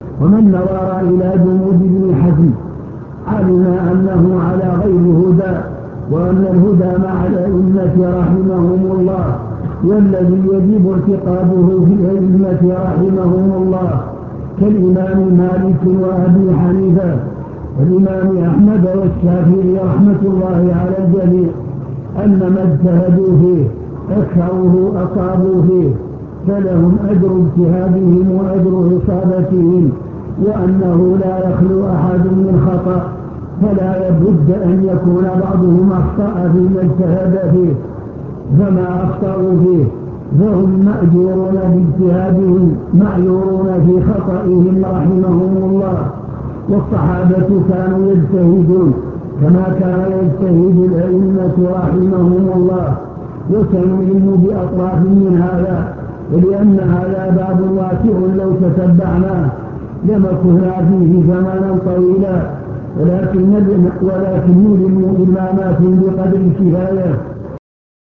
المكتبة الصوتية  تسجيلات - محاضرات ودروس  الرد على ابن حزم